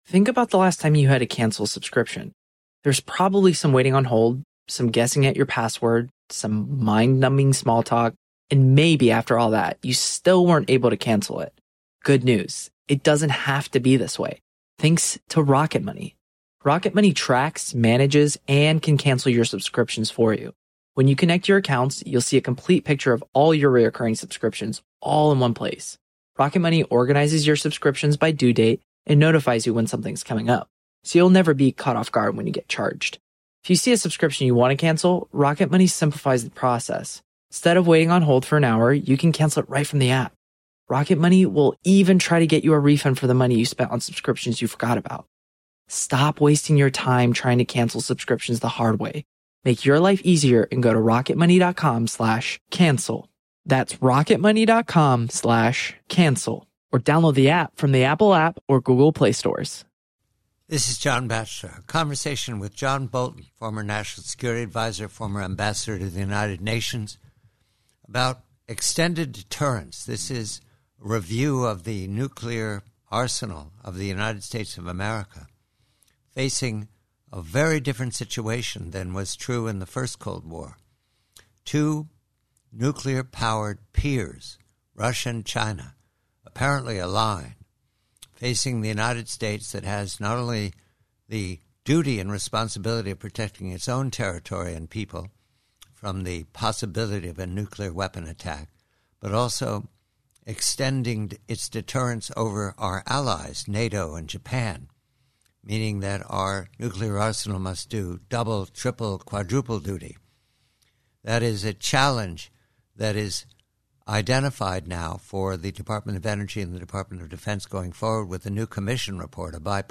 PREVIEW: From a longer conversation with John Bolton re the need to update and reorganize the nuclear weapon arsenal for the challenge of two nuclear armed peers, Russia and the PRC. John Bolton calls it a three-sided game that will be expensive and necessary.